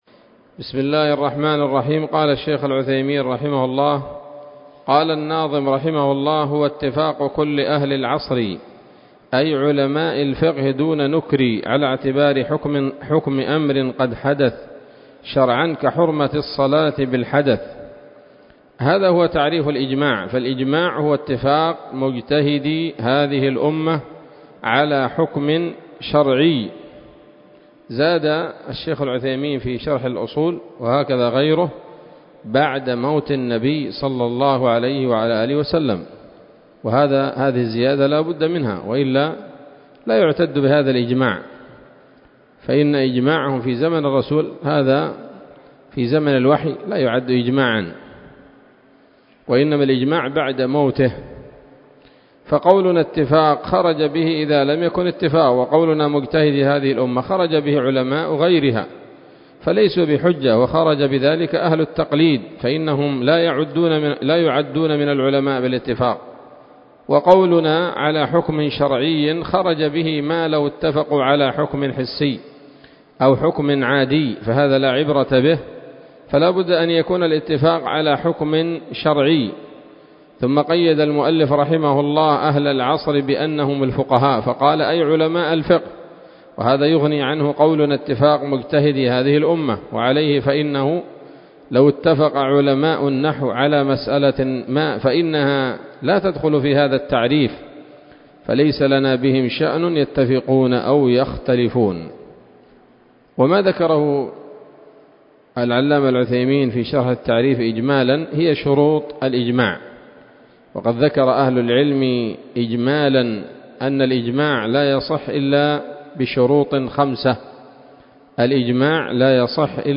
الدرس الرابع والخمسون من شرح نظم الورقات للعلامة العثيمين رحمه الله تعالى